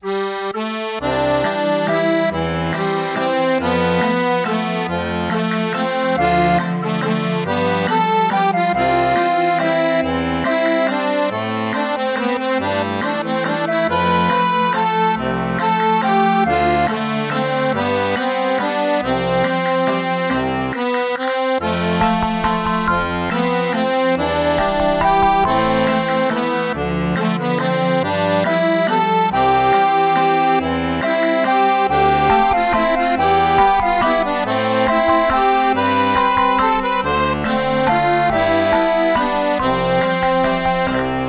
【イメージ】お買いもの・陽気な町 など